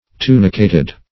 Tunicate \Tu"ni*cate\, Tunicated \Tu"ni*ca`ted\, a. [L.